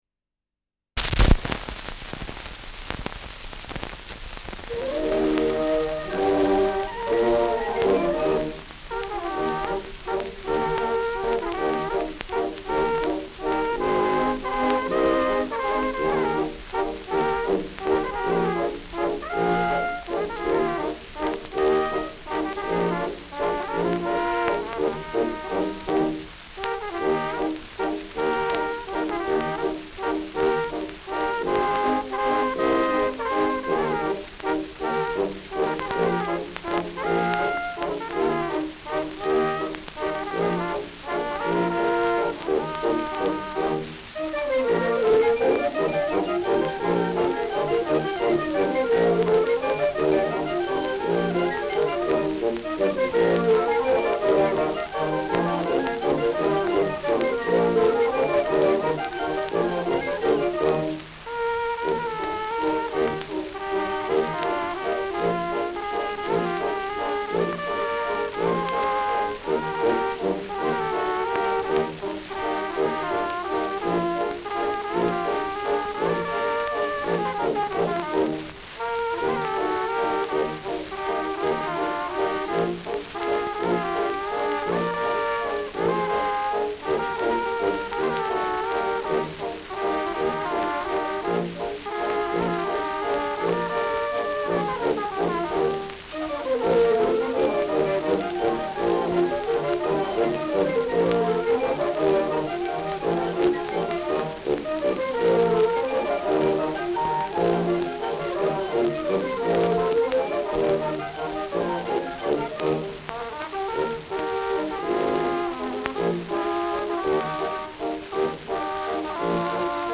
Schellackplatte
Rauschen präsent
Kapelle Peuppus, München (Interpretation)